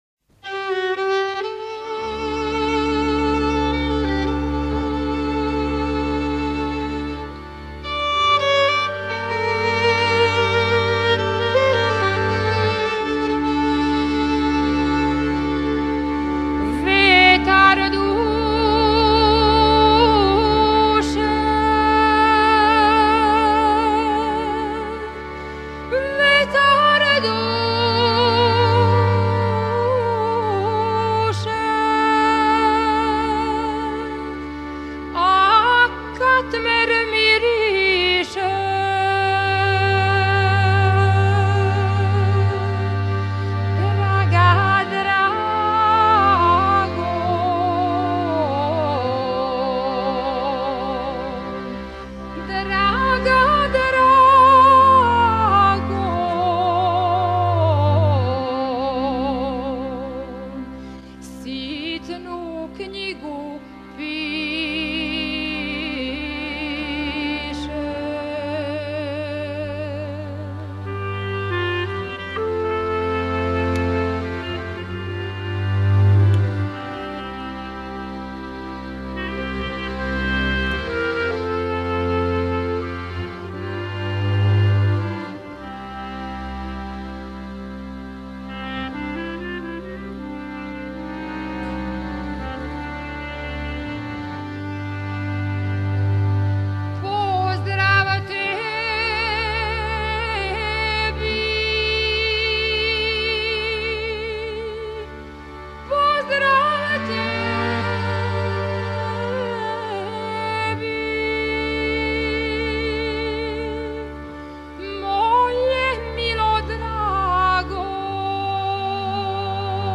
Напомена: Љубавна песма.